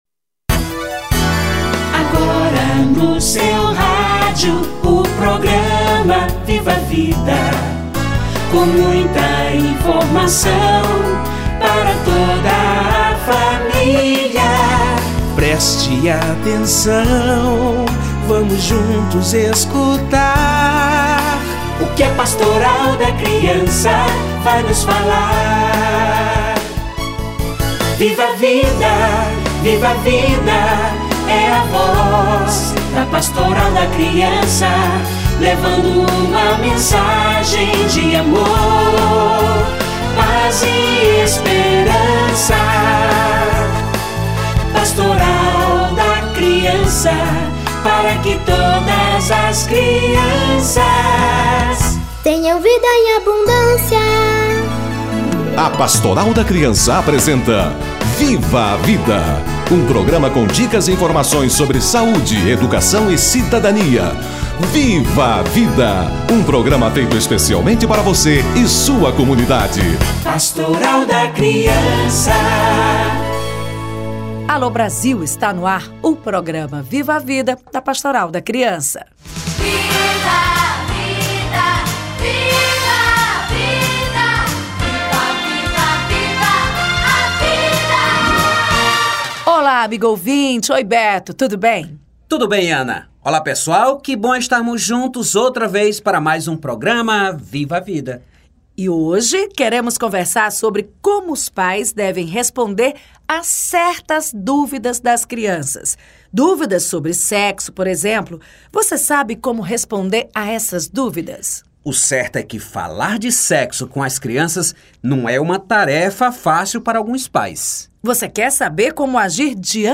Sexualidade: como responder as dúvidas das crianças - Entrevista